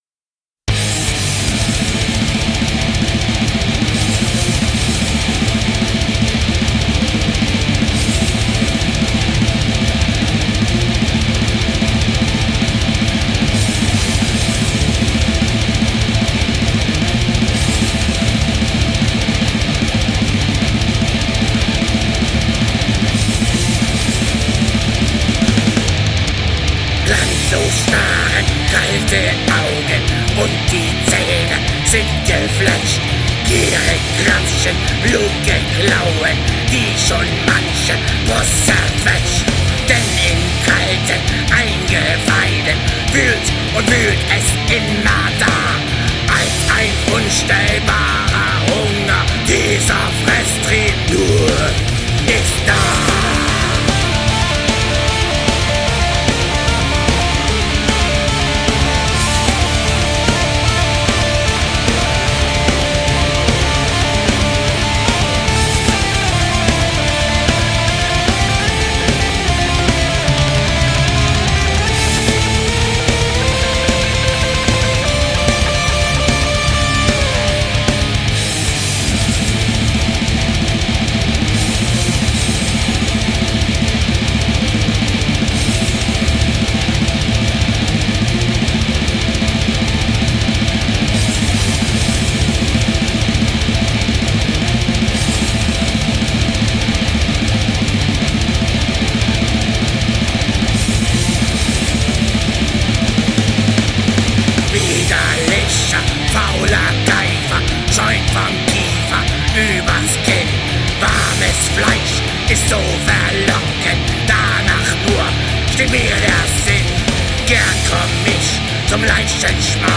Black / Pagan